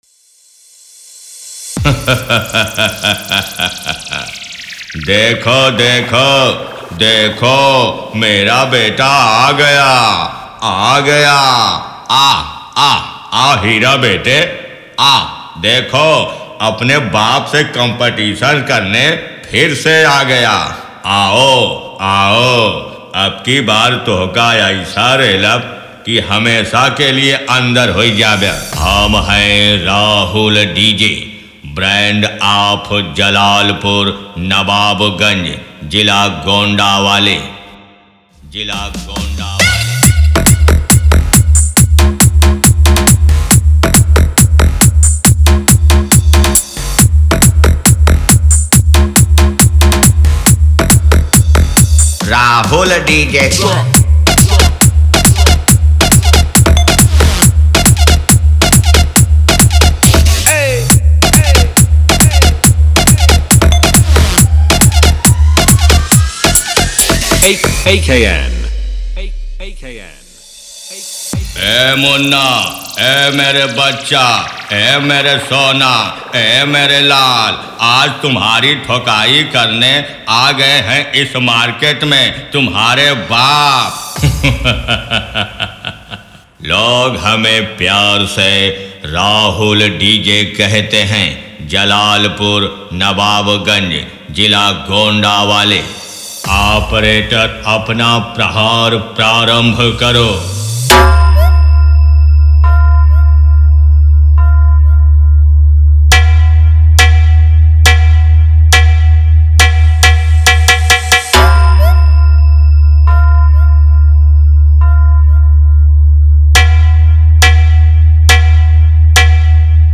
Dialogue DJ Beat 2025, Bass Boosted DJ Remix
Powerful Bass DJ Track
Party DJ Song